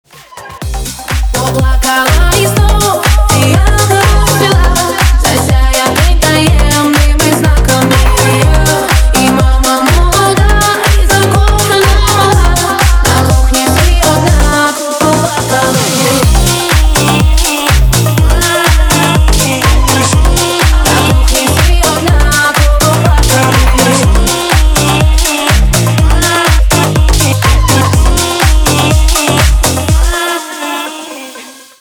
• Качество: 320 kbps, Stereo
Ремикс
Украинские